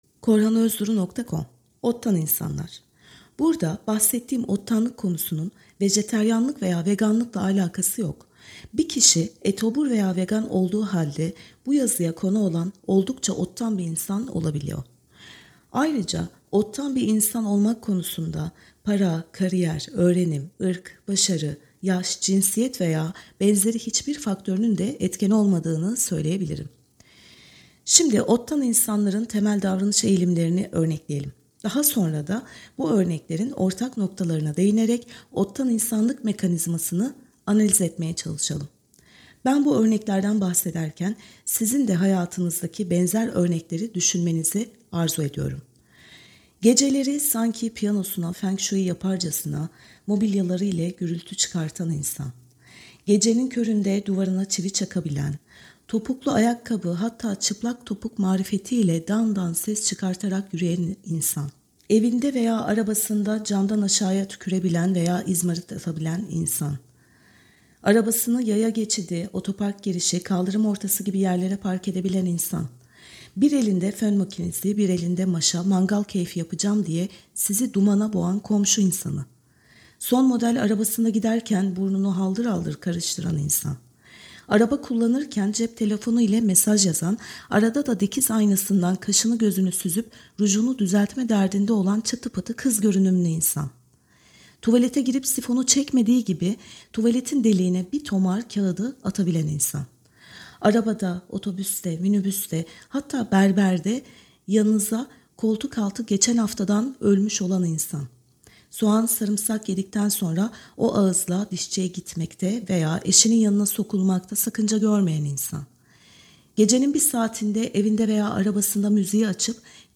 OttanInsanlarSesliOkuma.mp3